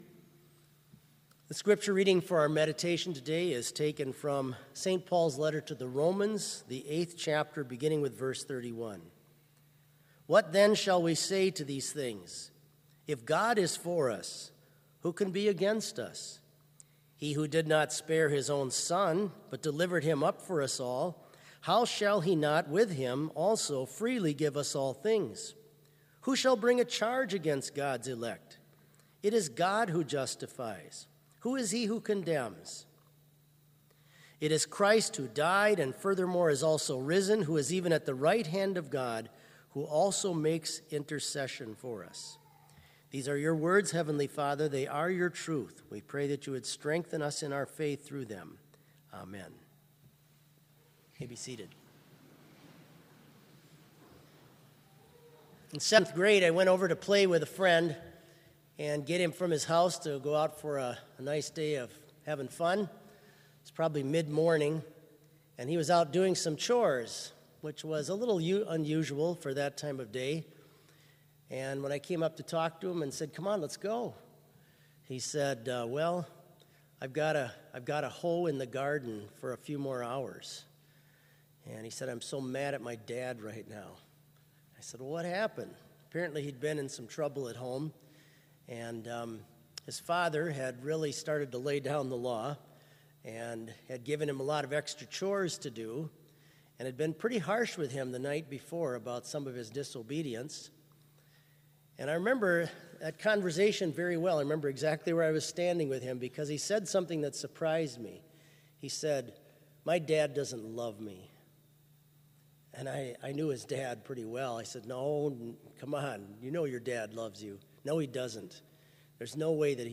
Complete service audio for Chapel - October 21, 2019